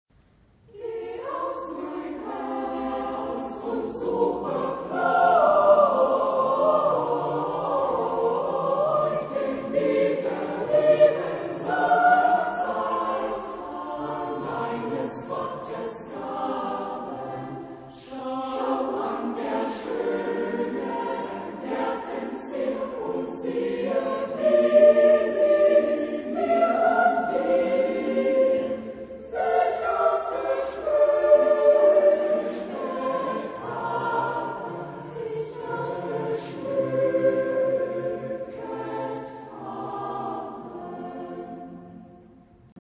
Género/Estilo/Forma: Sagrado
Tipo de formación coral: SATB  (4 voces Coro mixto )
por Knabenchor Frankfurt (Oder)
Ref. discográfica: 7. Deutscher Chorwettbewerb 2006 Kiel